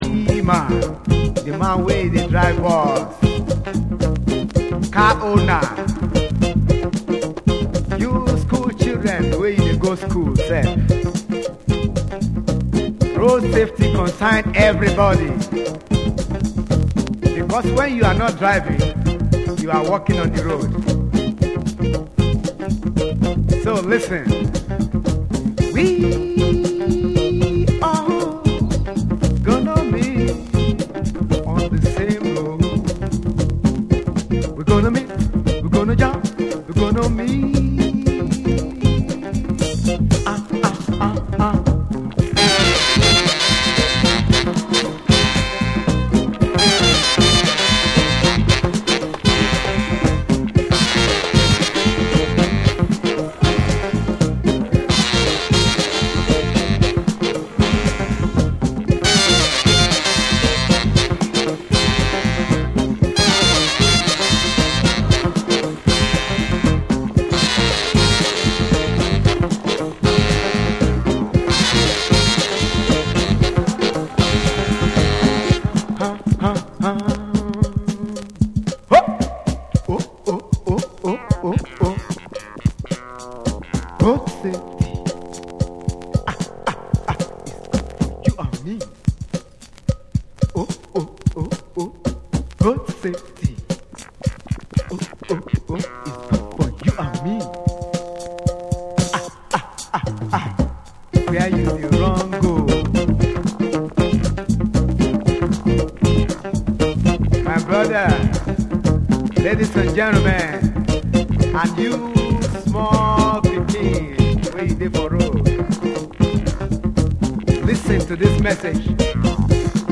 WORLD